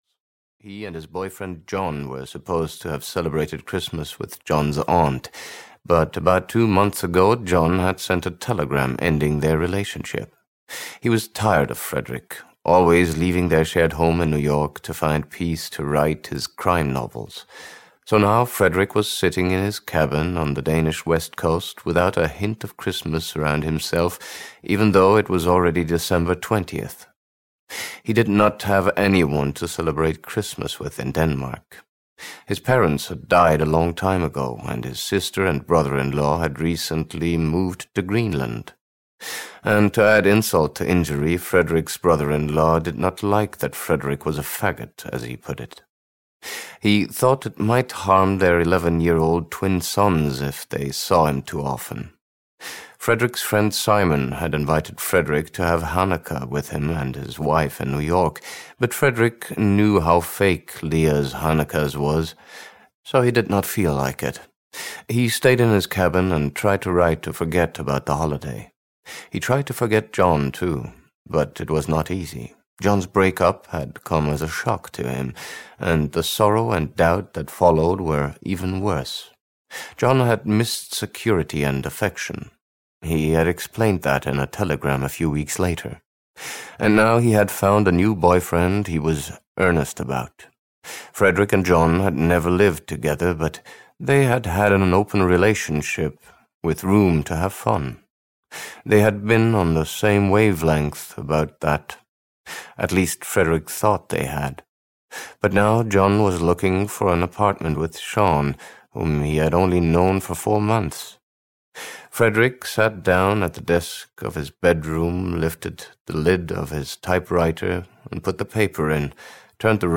Cabin Fever 3: A Change of Heart (EN) audiokniha
Ukázka z knihy